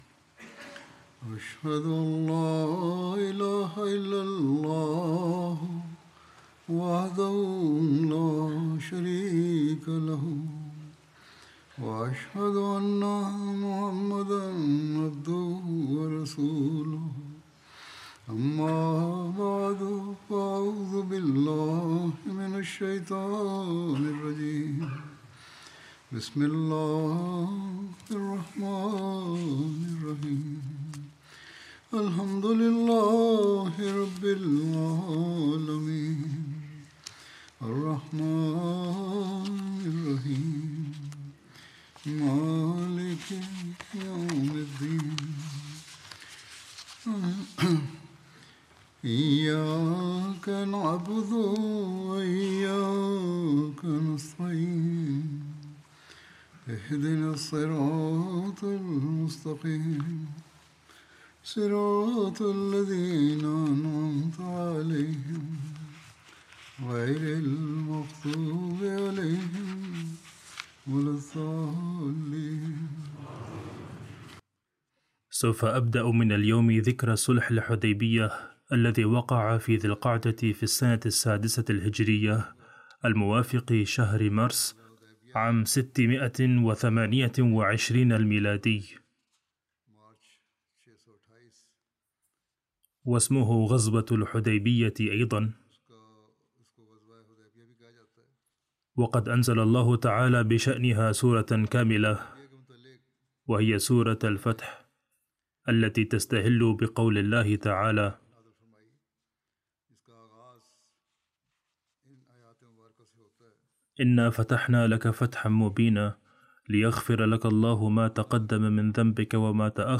Arabic Translation of Friday Sermon delivered by Khalifatul Masih